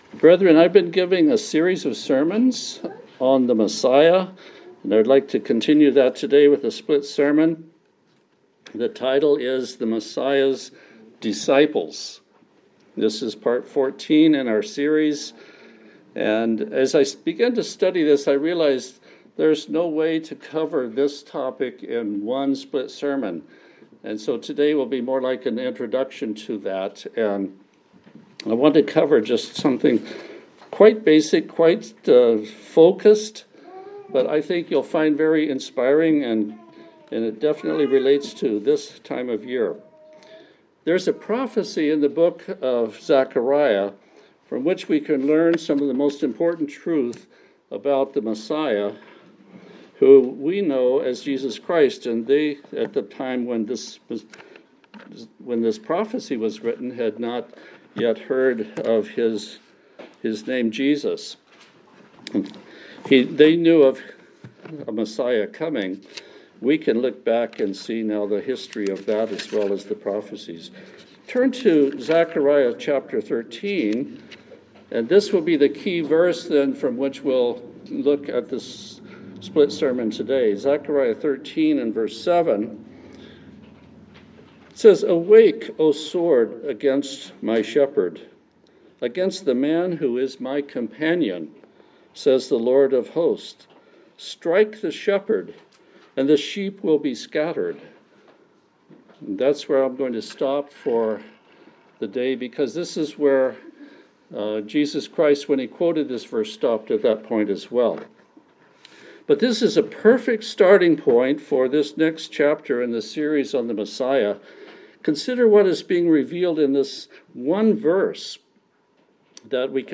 Sermons
Given in Olympia, WA Tacoma, WA